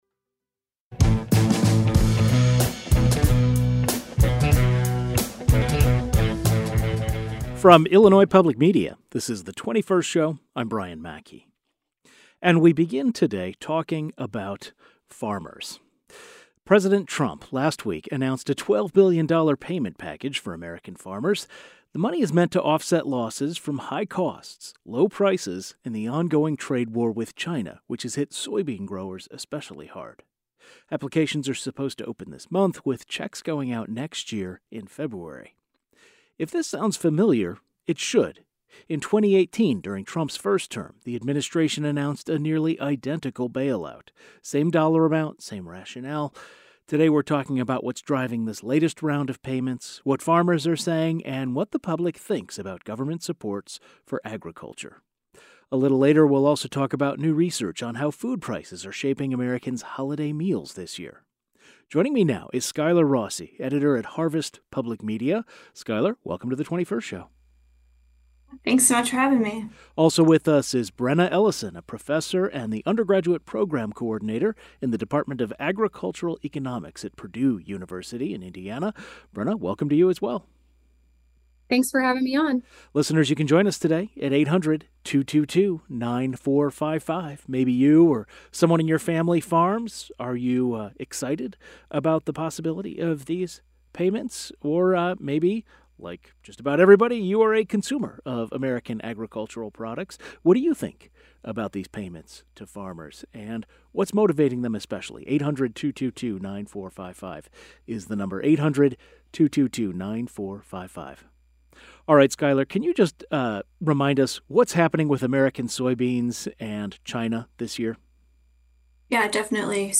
The 21st Show is Illinois' statewide weekday public radio talk show, connecting Illinois and bringing you the news, culture, and stories that matter to the 21st state.
A journalist who covers agriculture as well as an agricultural economics expert discuss what is driving this latest round of payments, what farmers are saying, and how rising food prices will affect upcoming holiday meals.